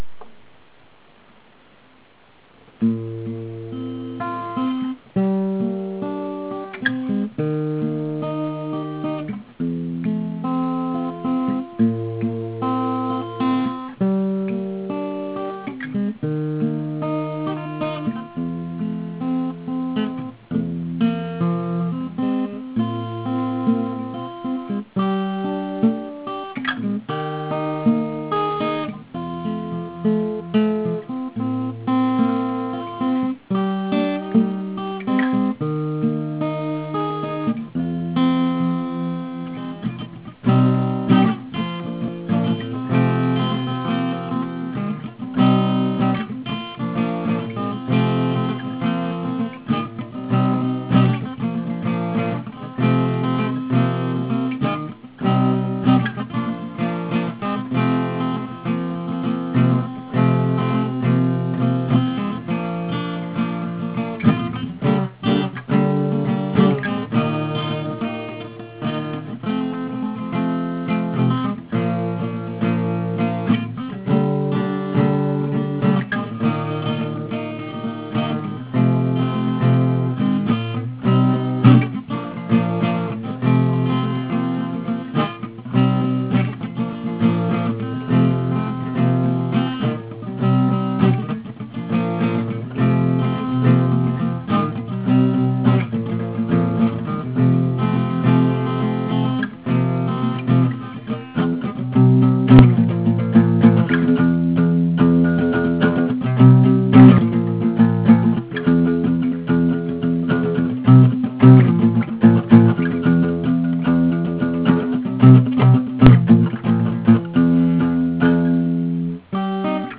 זו הנגינה או האקורדים בכלופן של השיר שלי "בת המלך"..
הקלטה ראשונה שלי, הוקלט בMP3 ב3 לפנות בוקר-אז נא להתחשב!!
הגיטרה לא מכוסה אבק? :-P,
מעולה [כמובן חוץ מאיכות ההקלטה]